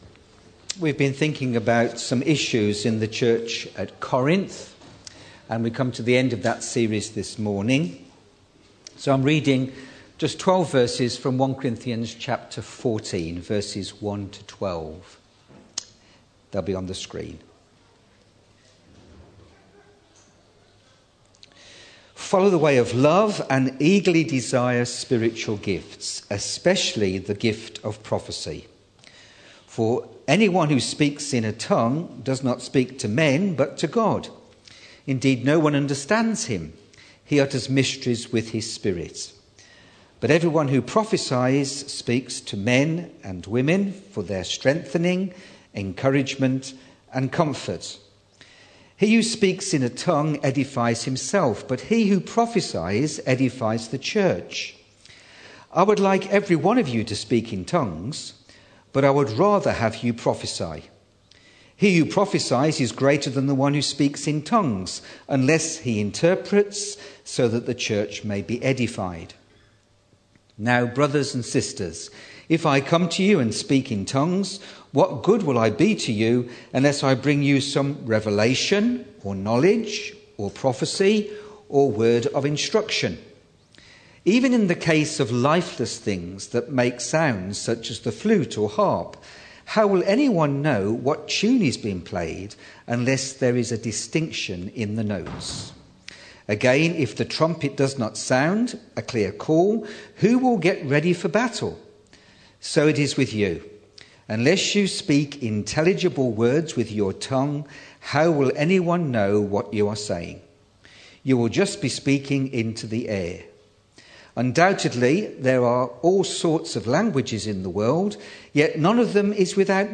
It was quite a long series at Purley Baptist but just five in this section Divisions in the church over leaders -1 Corinthians 1 10-17 Sexual immortality – 1 Cor 6 12-20 Baptism – 1 Corinthians 10 1-13 speaking in tongues – 1 Cor 12 and 14 Prophecy – 1 Cor 14 1-15